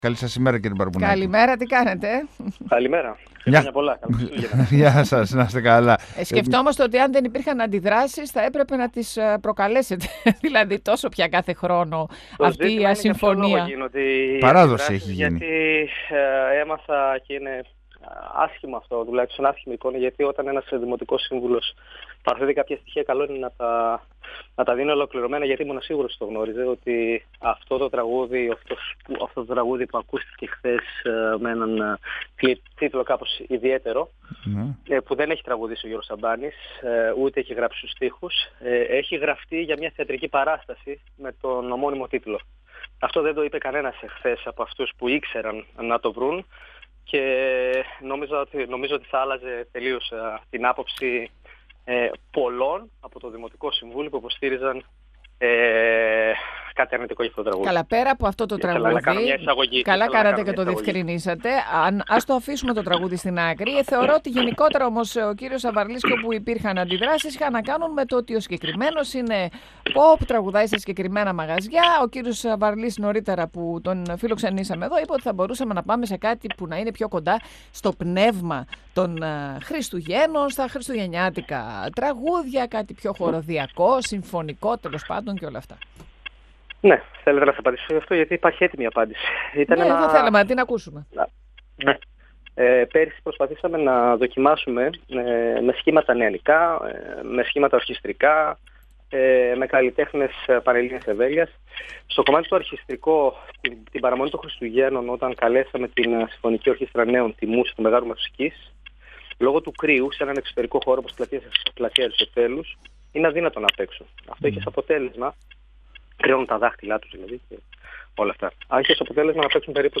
Ο αντιδήμαρχος Αλέξανδρος Μπαρμπουνάκης, στον 102FM του Ρ.Σ.Μ. της ΕΡΤ3